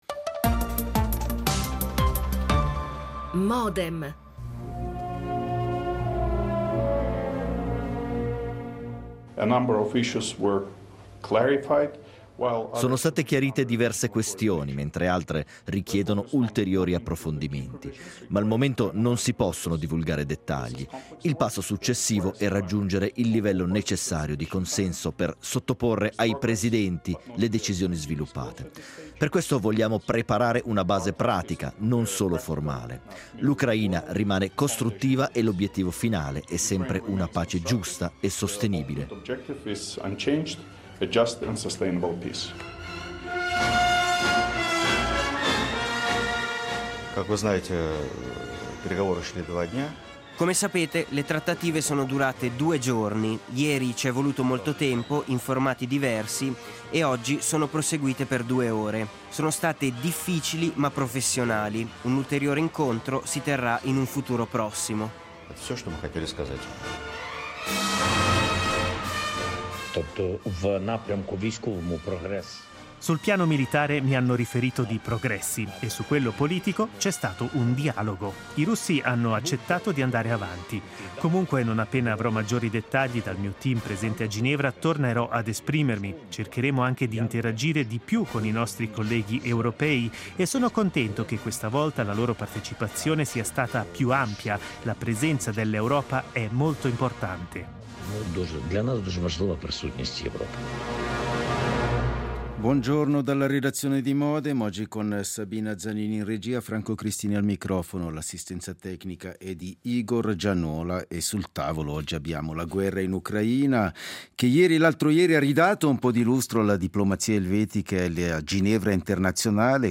Ne discutiamo con due giornalisti che regolarmente ci aiutano a comprendere e raccontare questa guerra:
L'attualità approfondita, in diretta, tutte le mattine, da lunedì a venerdì